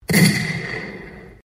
Sham Gunshot
gunshot_WAH537D.mp3